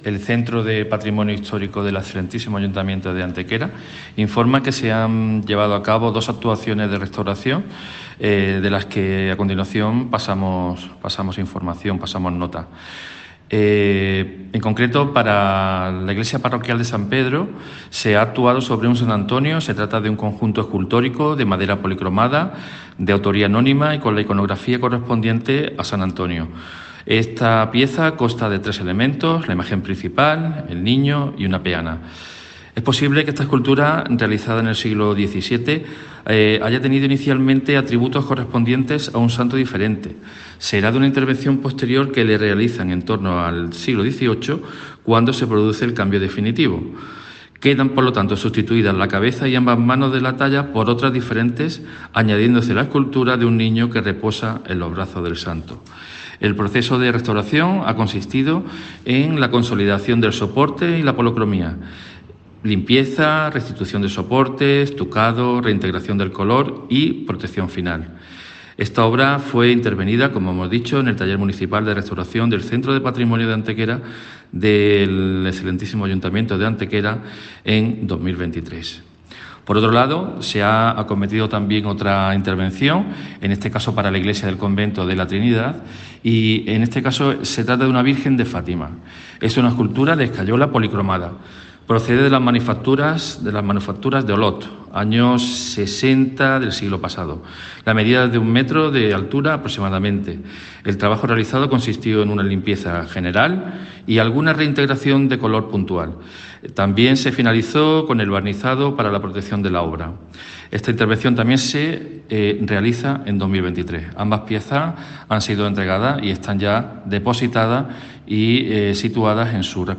El concejal delegado de Cultura y Patrimonio Histórico, José Medina Galeote, informa de dos trabajos realizados por el Taller Municipal de Restauración en torno a las restauraciones de dos esculturas procedentes tanto de la iglesia de San Pedro como de la Trinidad.
Cortes de voz